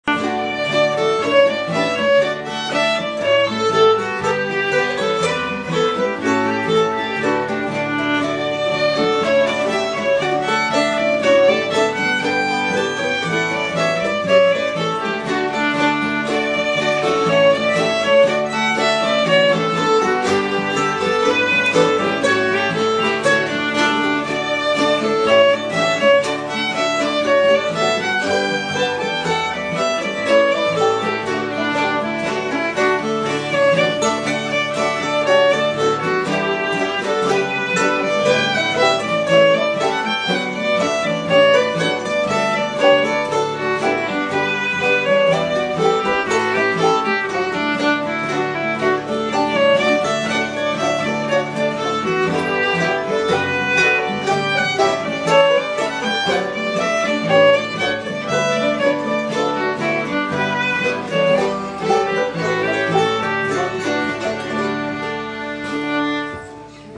Key: D
Form: Reel
M: 4/4